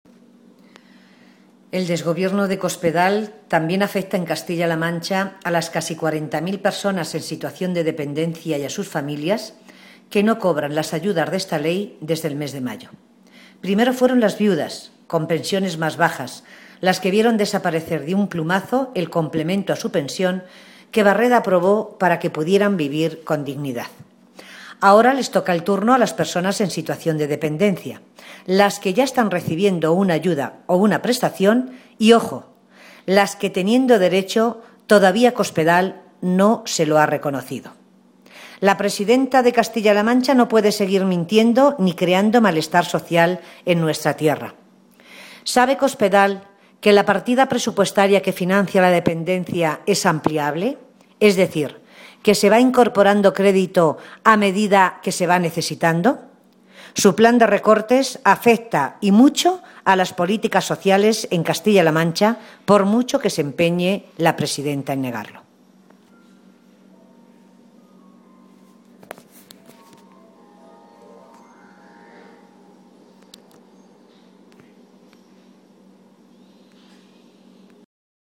Matilde Valentín, diputada regional del PSCM-PSOE
Cortes de audio de la rueda de prensa